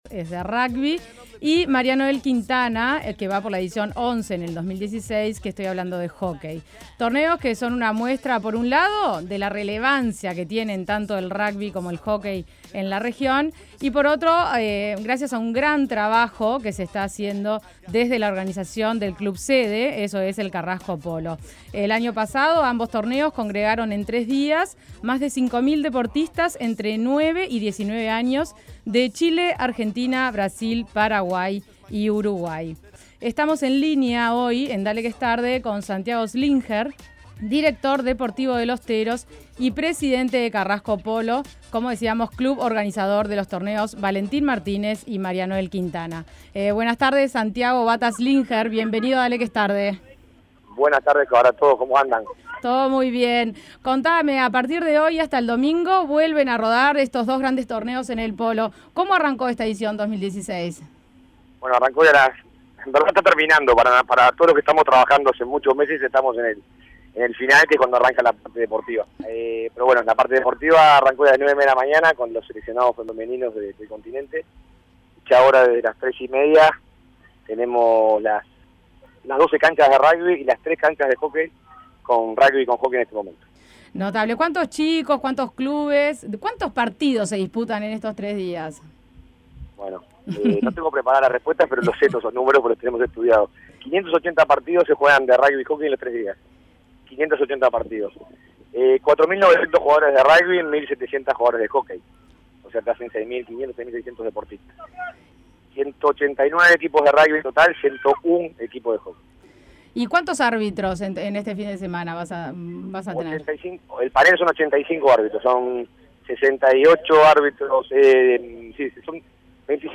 Es un torneo que es referente en la región y que ya tiene inscripciones para la edición 2017.